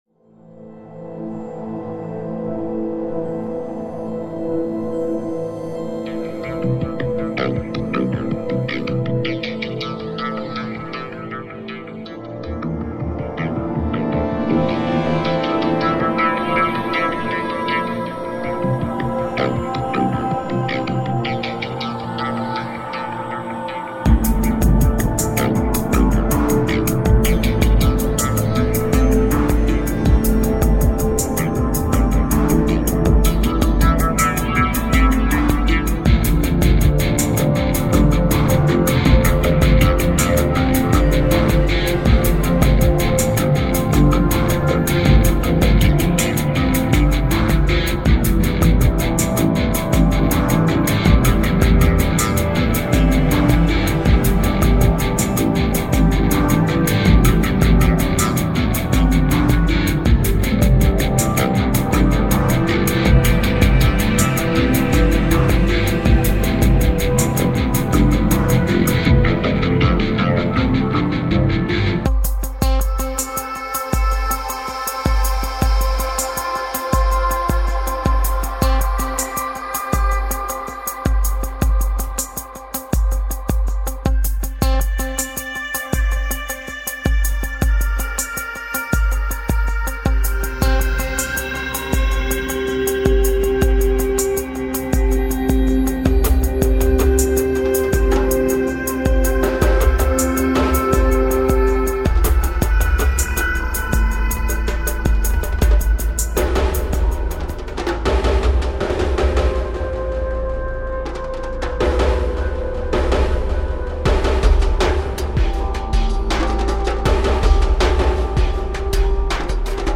Žánr: Electro/Dance